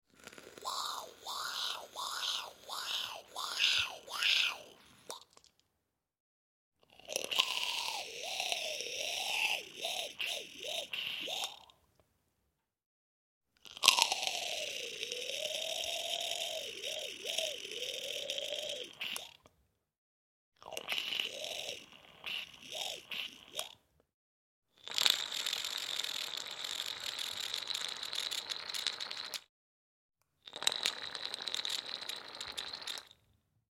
На этой странице собраны звуки электронных сигарет: шипение, бульканье, парение и другие эффекты.
Процесс использования электронной сигареты